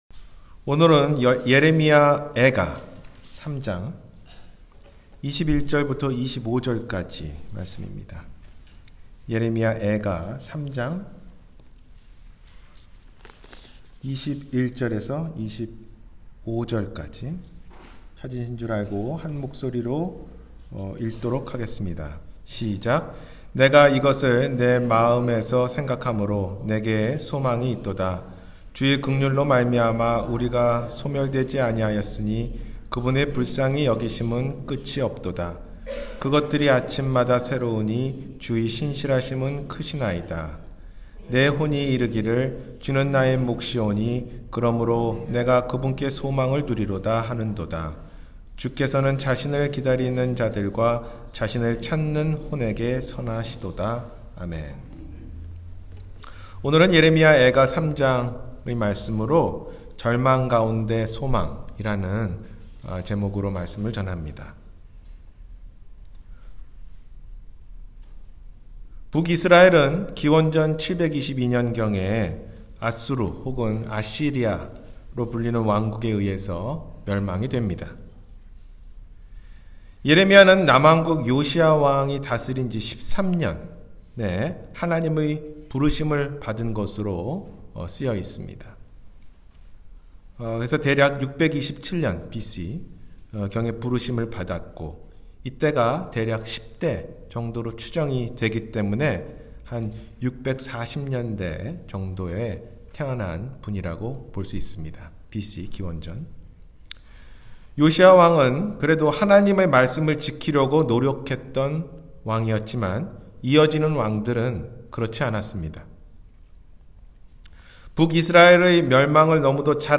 절망 가운데 소망-주일설교